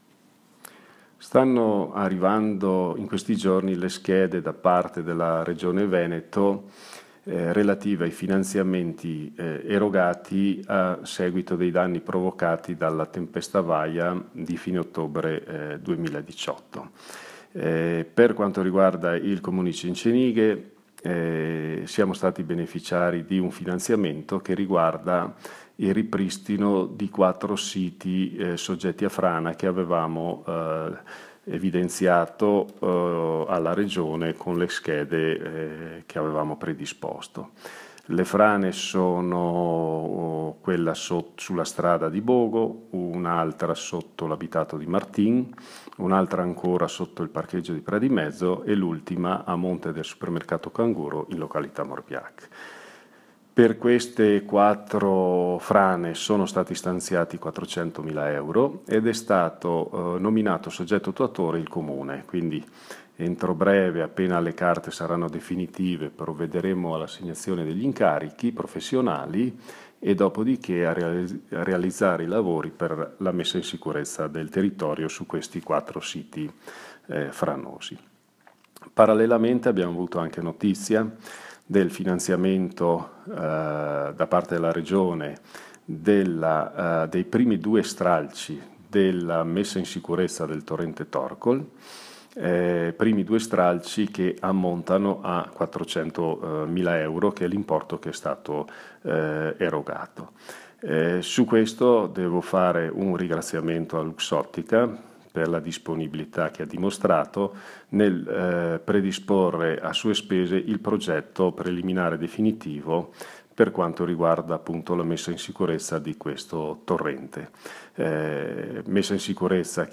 IL SINDACO MAURO SOPPELSA NELLA RASSEGNA STAMPA DELLE 8.05 SPIEGA QUALI SONO GLI INTERVENTI NECESSARI A CENCENIGHE.